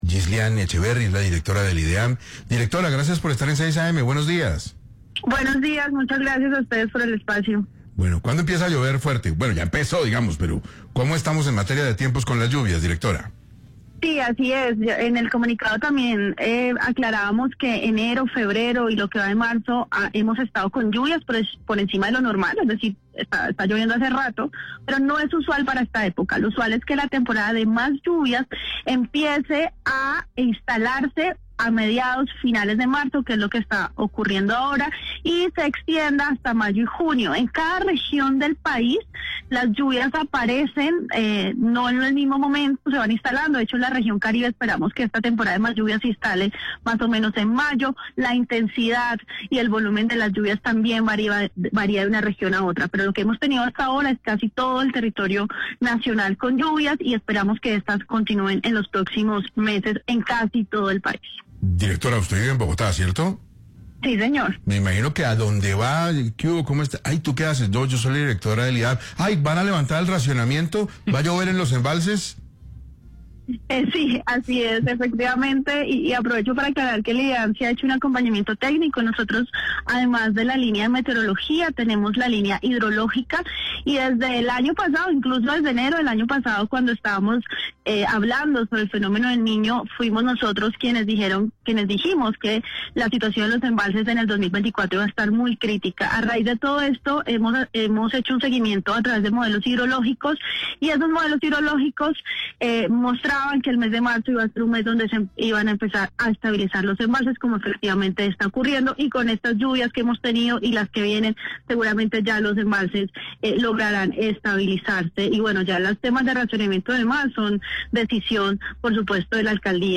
En entrevista con 6AM de Caracol Radio, la directora de la entidad, Ghisliane Echeverry, aseguró que las lluvias durante este inicio del año están por encima de los niveles normales.